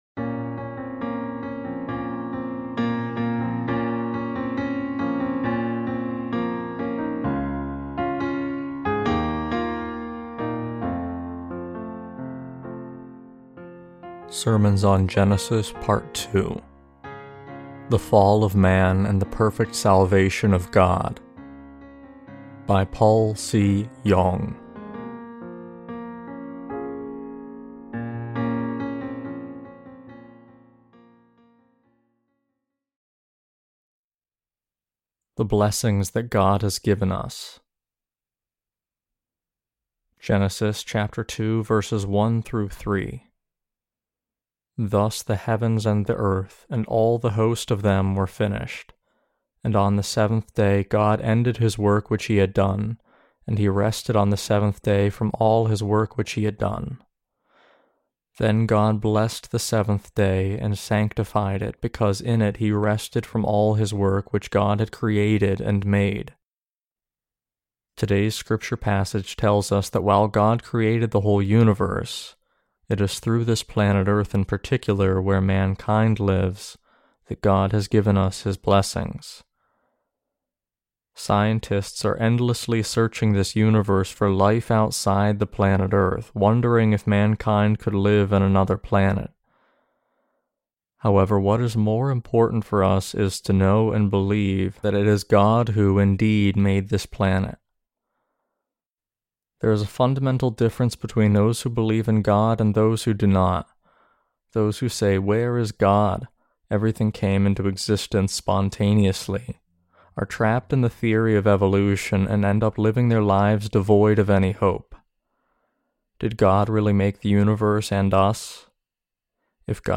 Sermons on Genesis (II) - The Fall of Man and The Perfect Salvation of God Ch2-1.